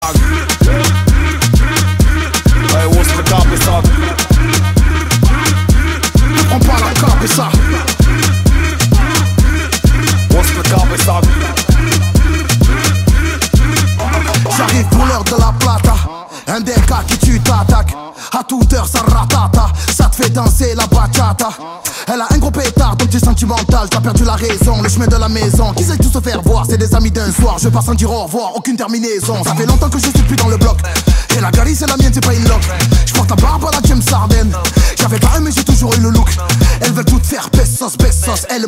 Catégorie Rap / Hip Hop